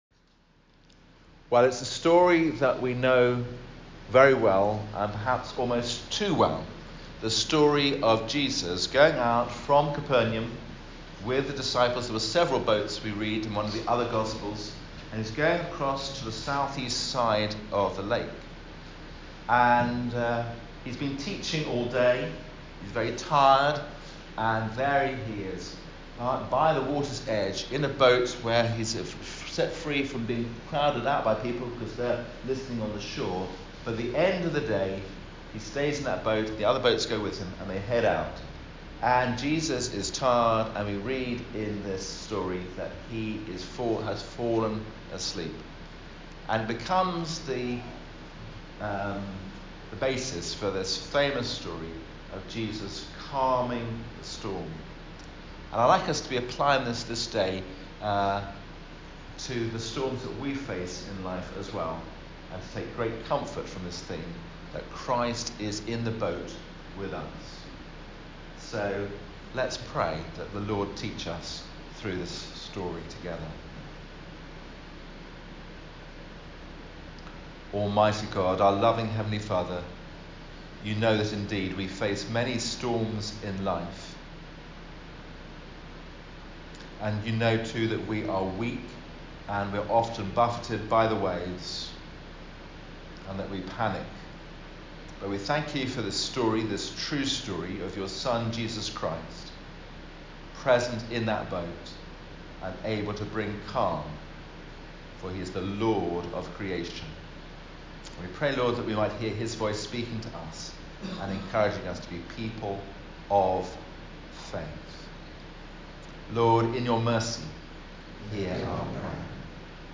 That was the strapline of my sermon in the morning at All Age Worship: in so many areas of life we are indeed ‘sunk without Jesus’.